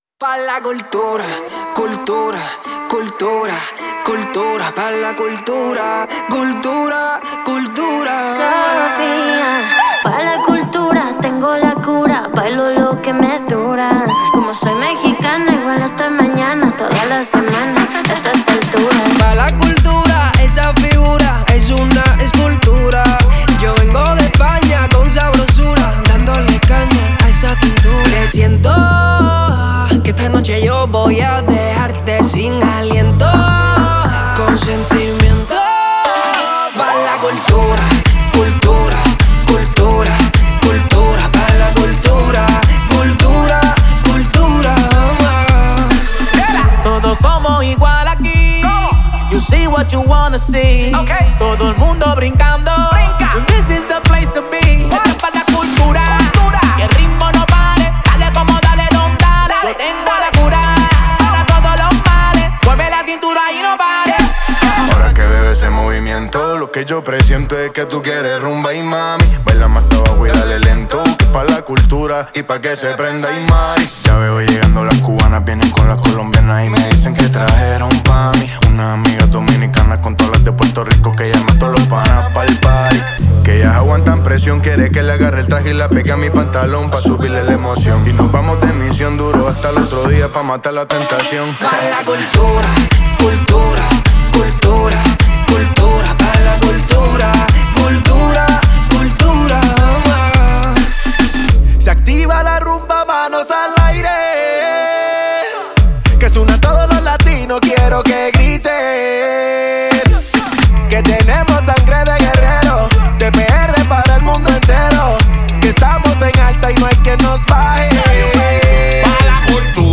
это зажигательный трек в жанре латинского попа и реггетона